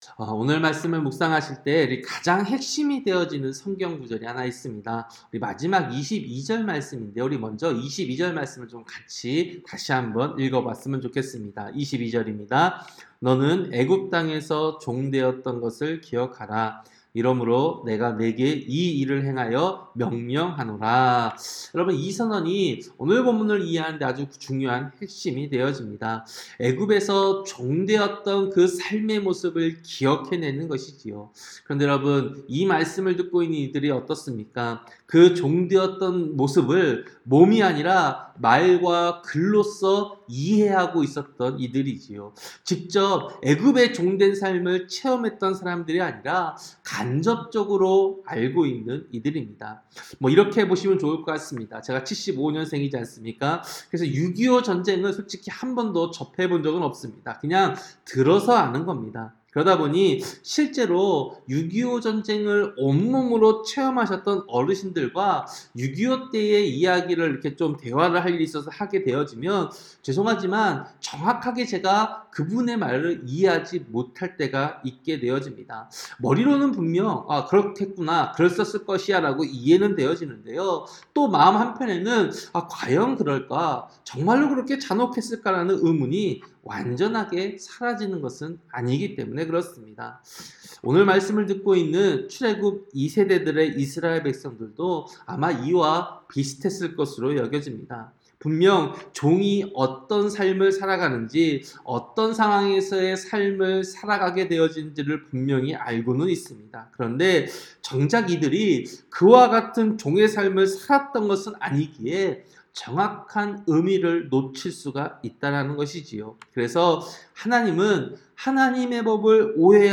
새벽설교-신명기 24장